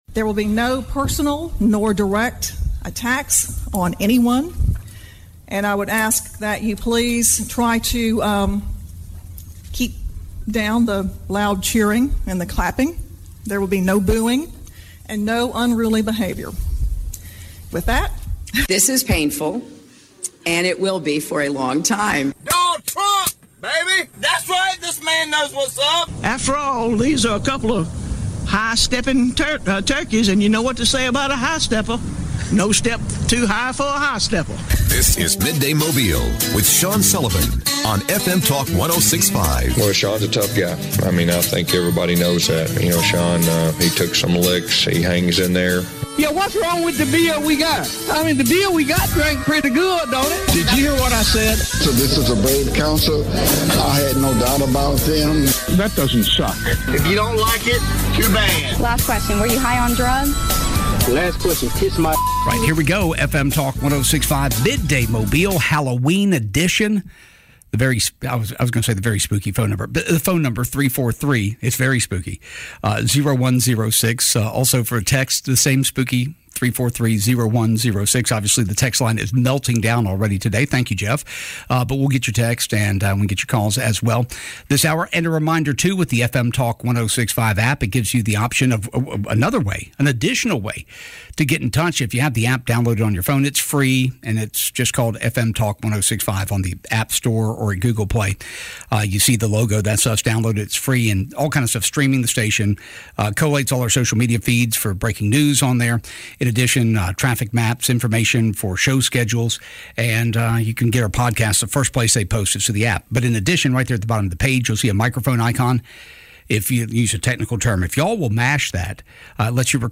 Mobile County District Attorney Keith Blackwood joins the show.